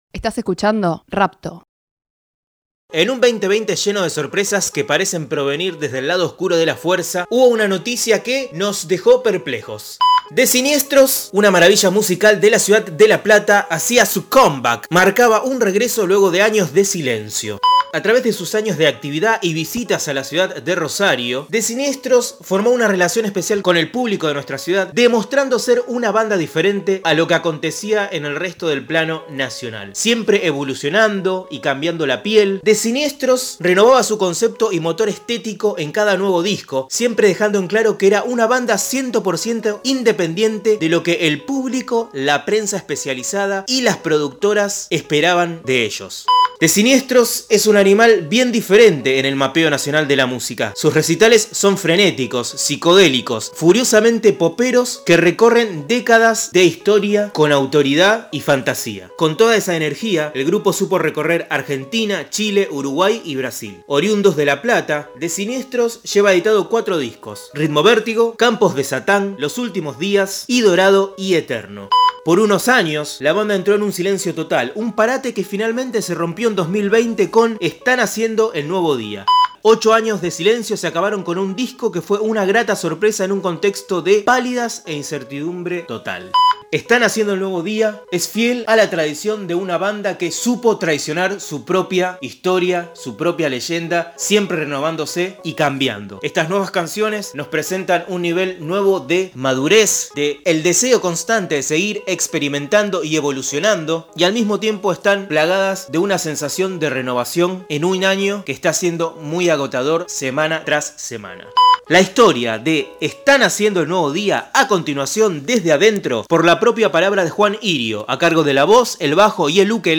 Desde adentro es un podcast que busca revelar las instancias de producción de las últimas novedades de la música independiente. Los discos más recientes desde la voz de sus protagonistas.
La canción que cierra el podcast es “Los cuerpos”.